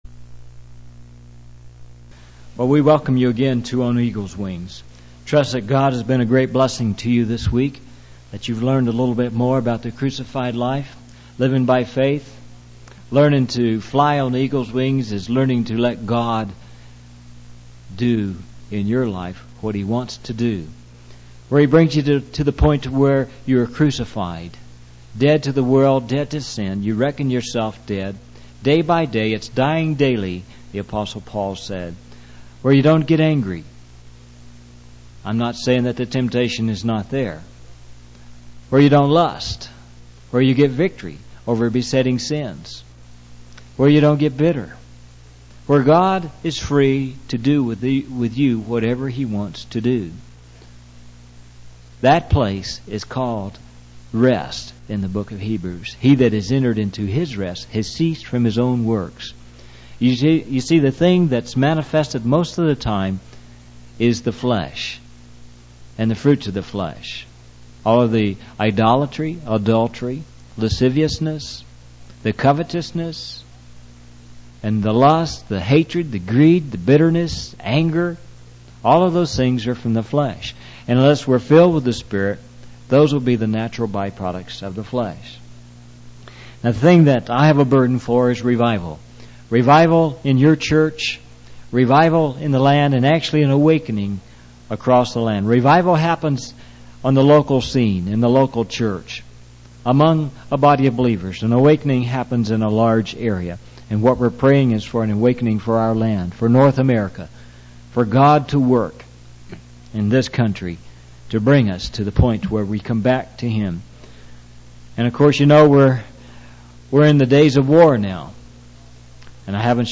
In this sermon, the speaker focuses on the story of Moses and how he learned to trust and rely on God in the desert. The speaker emphasizes the importance of waiting on God and not accepting the lies of Satan. They also highlight the significance of praising God and giving thanks in all circumstances.